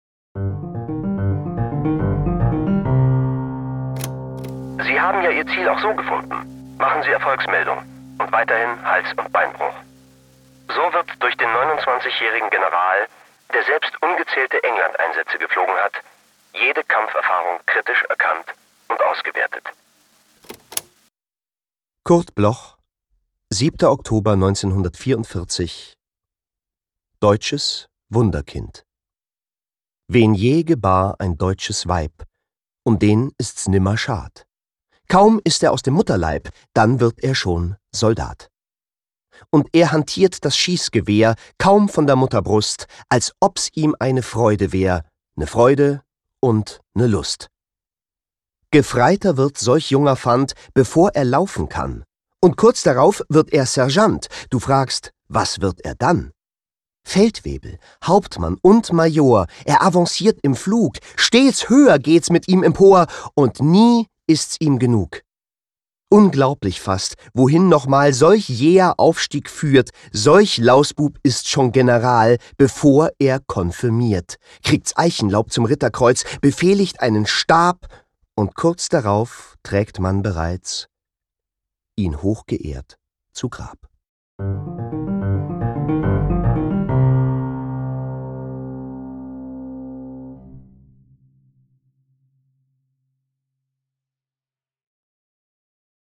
vorgetragen von